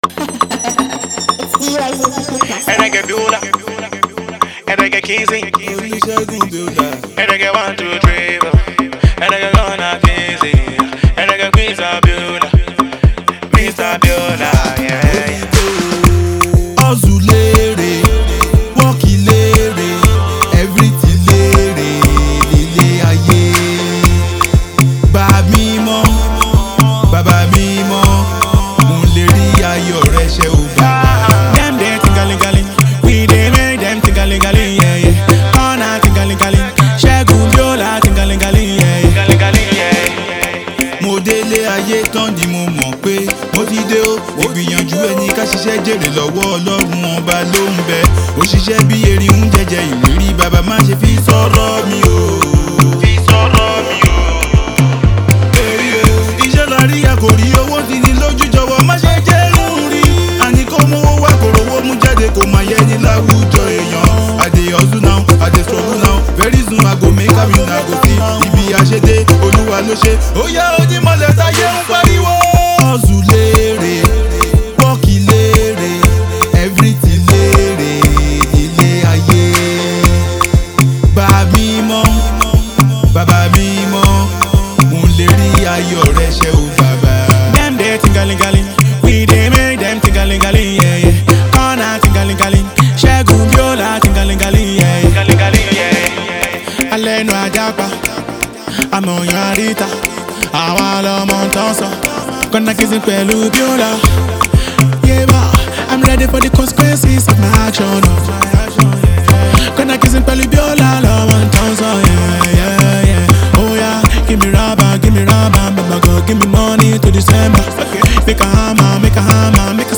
Gospel Juju